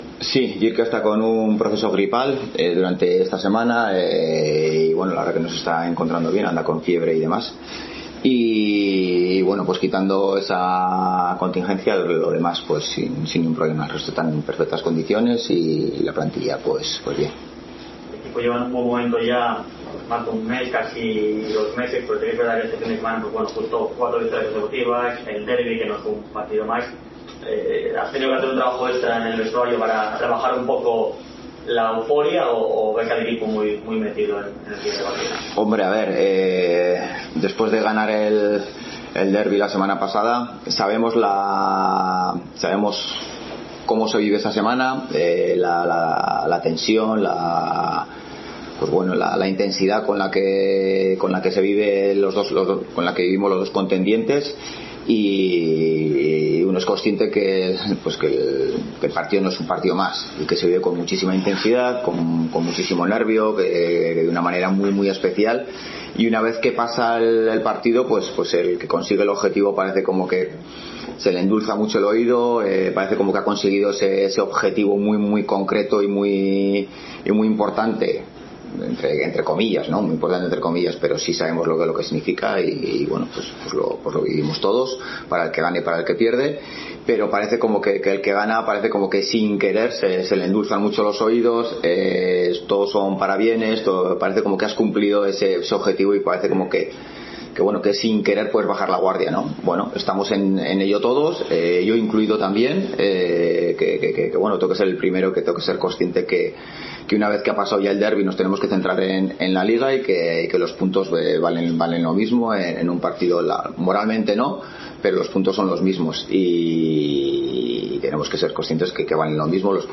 Rueda d eprensa Ziganda (previa Alcorcón)